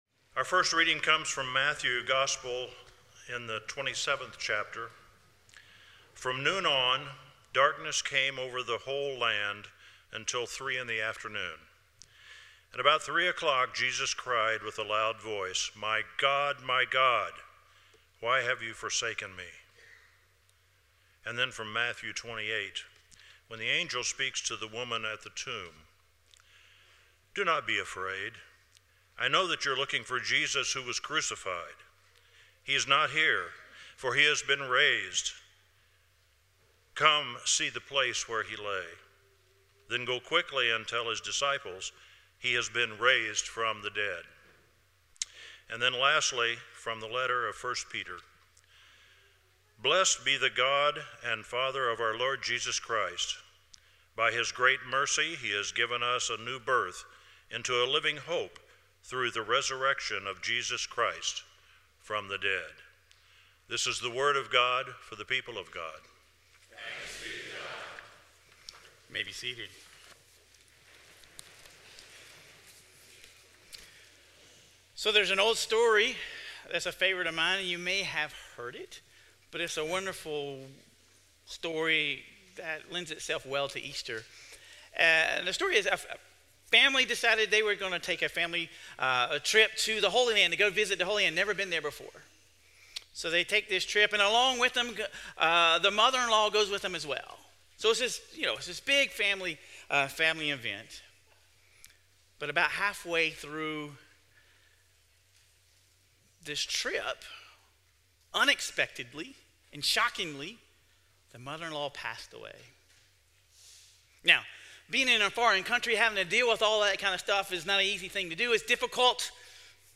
Sermon Reflections: In what areas of your life have you placed your hope, and how has that affected you during difficult times?